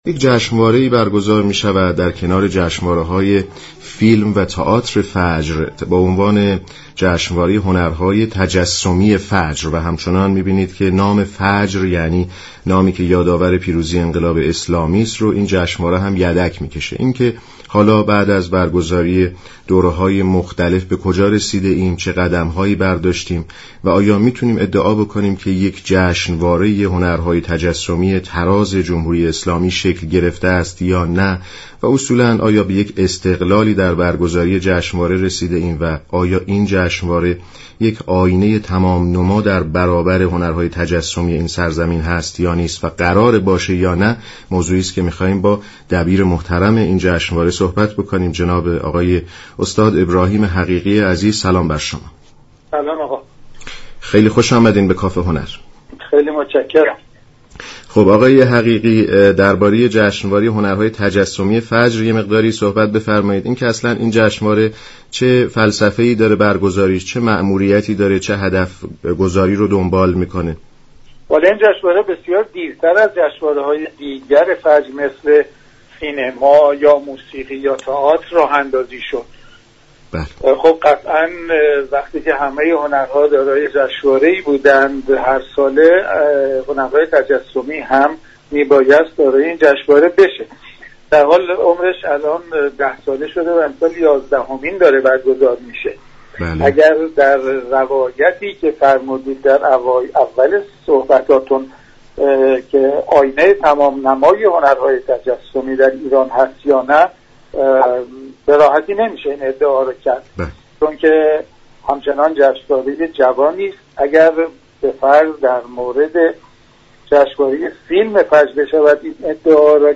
ابراهیم حقیقی دبیر یازدهمین جشنواره هنرهای تجسمی در گفت و گو با رادیو ایران گفت: افراد حرفه ای و خبره در این حوزه بیشتر در گالری های خود مشغول كار هستند و مشتاق دریافت جایزه آن با مبلغ اندك نیستند.